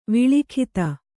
♪ viḷikhita